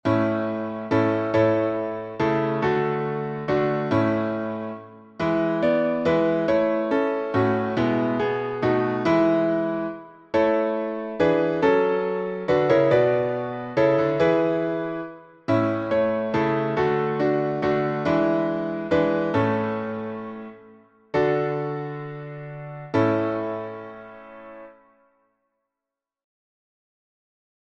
Key signature: A major (3 sharps) Meter: 7.7.7.7.